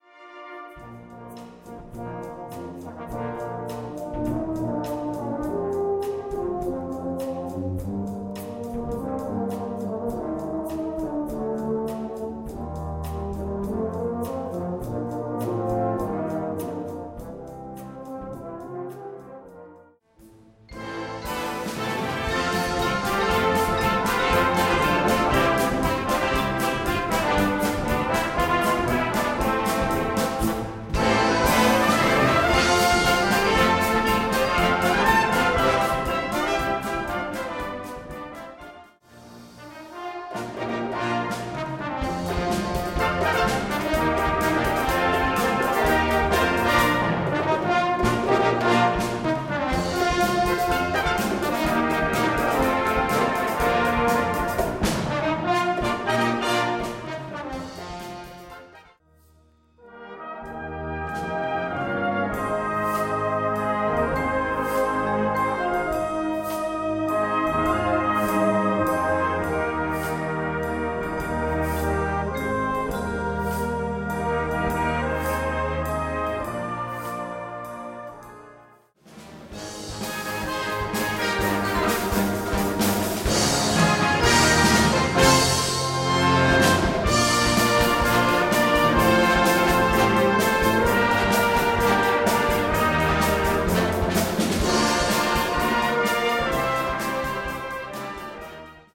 Gattung: Konzertant
Besetzung: Blasorchester